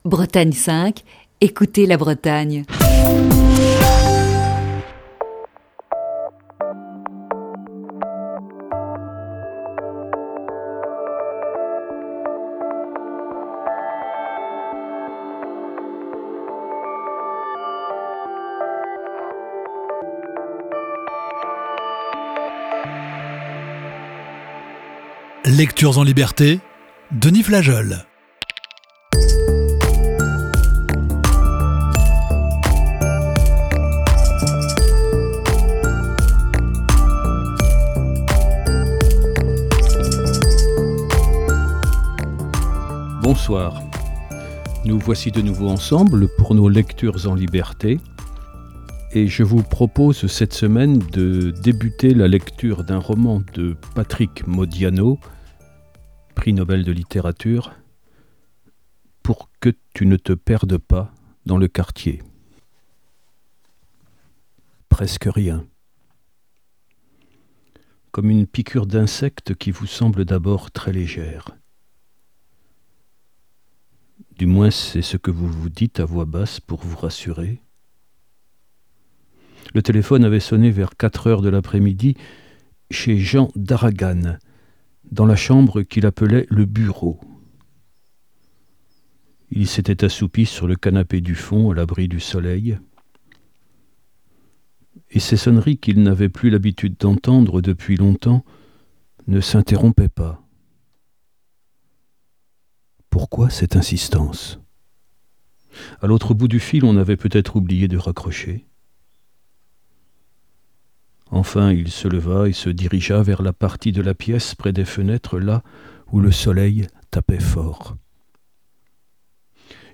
Voici ce soir la première partie de ce récit.